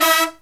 HIGH HIT03-L.wav